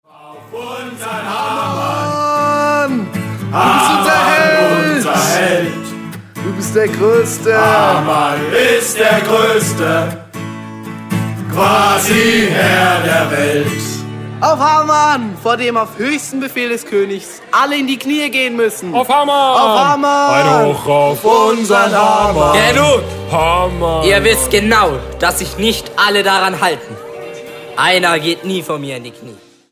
Musical-CD
14 Lieder und kurze Theaterszenen